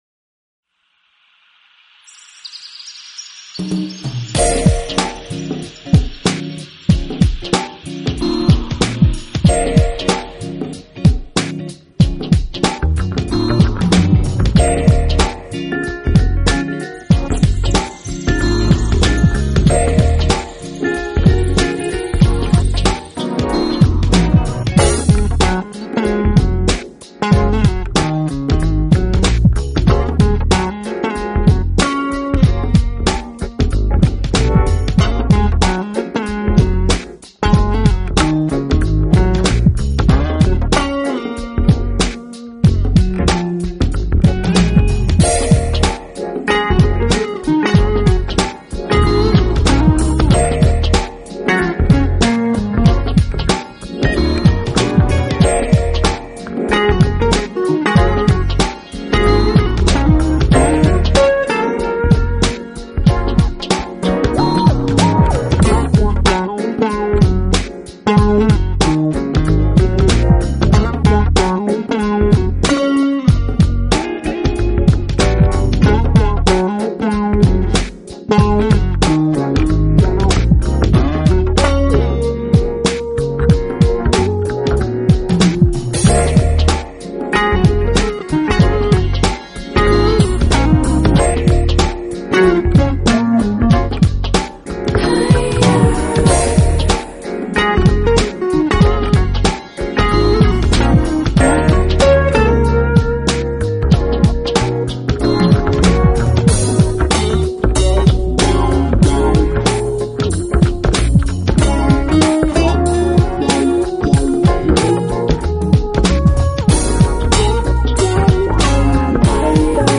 作为一位歌手，她有着精致而深情、技术出色的嗓音，作为音乐家，